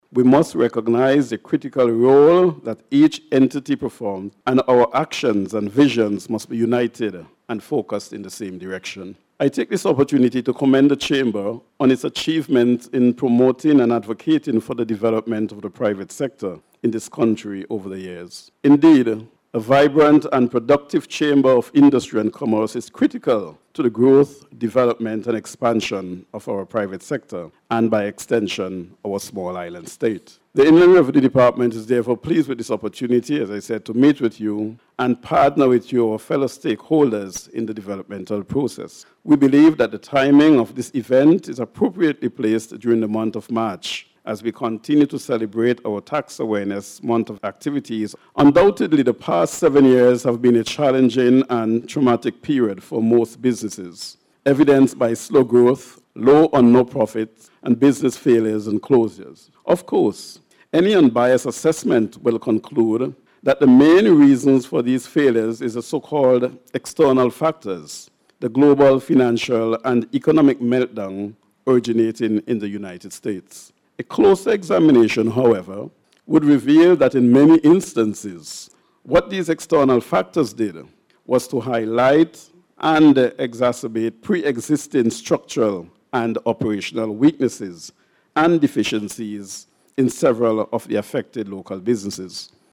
The Chamber of Industry and Commerce Hosted A Business Luncheon Yesterday
The featured speaker at the event was Comptroller of Inland Revenue Kelvin Pompey, who pointed out that a robust and sustained partnership between the public and private sector is crucial to fostering economic growth in St. Vincent and the Grenadines.